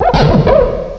cry_not_scrafty.aif